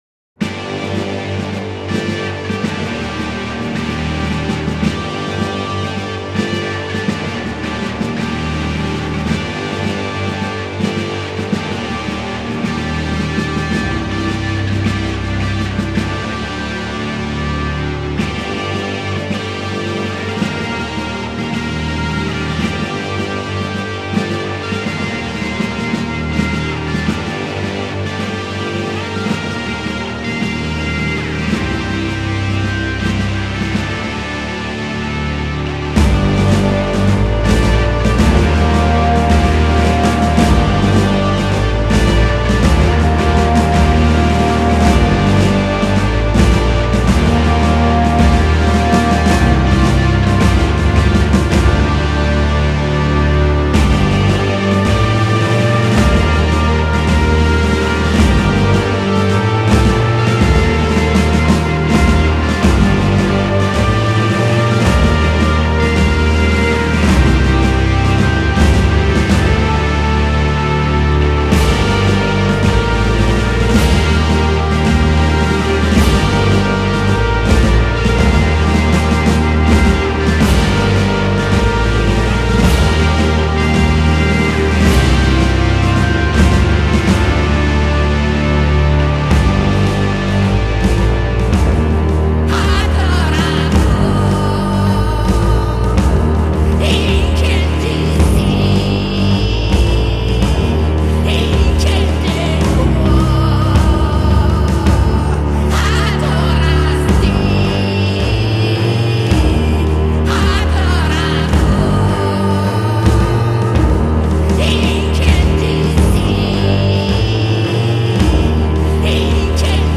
Sombres, incantatoires et mécaniques